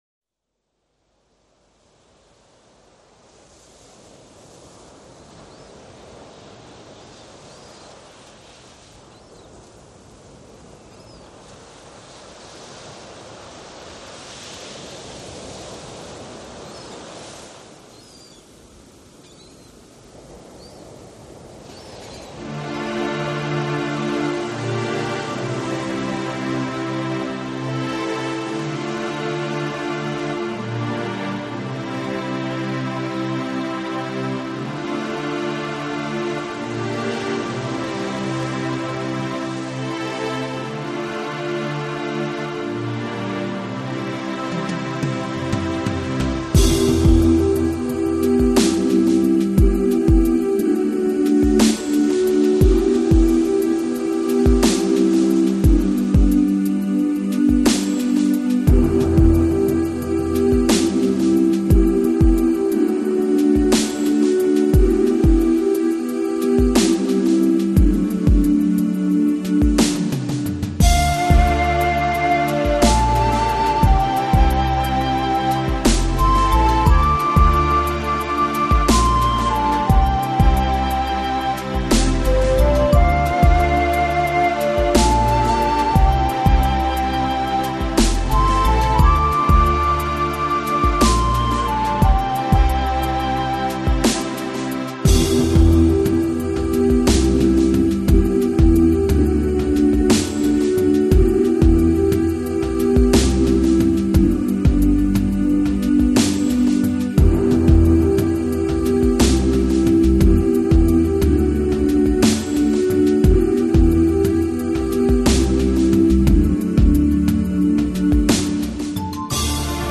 凯尔特音乐超级精选